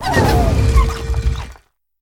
Cri de Forgelina dans Pokémon HOME.